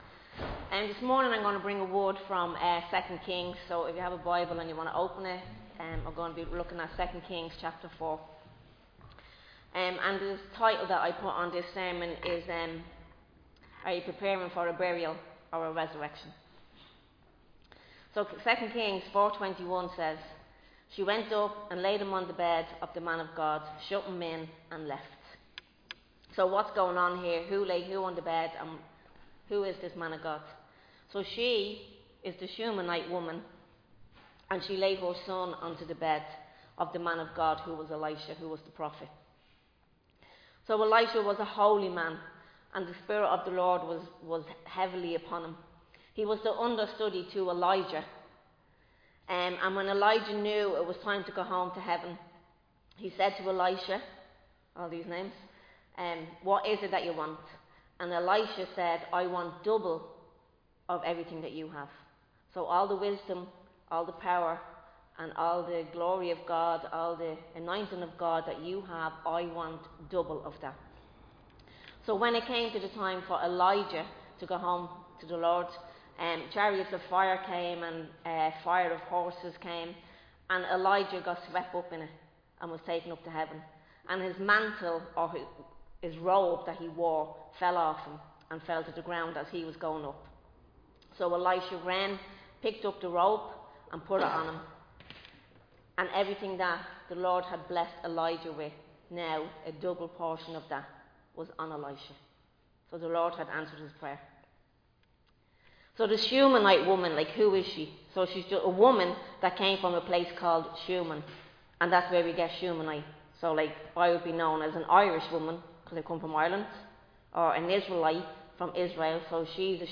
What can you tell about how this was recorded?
Recorded live in Liberty Church